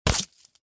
impact.mp3